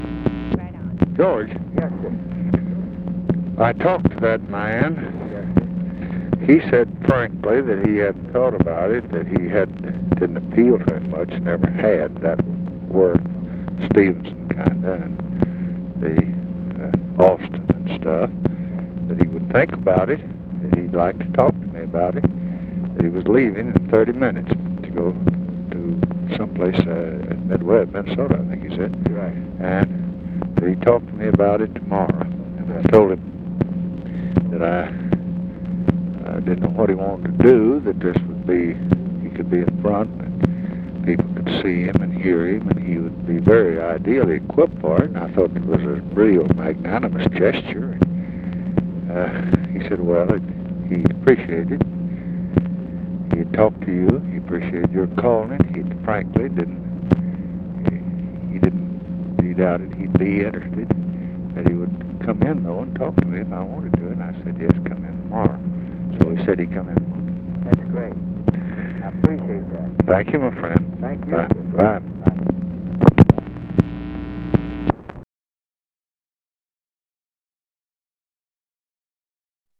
Conversation with GEORGE SMATHERS, November 21, 1968
Secret White House Tapes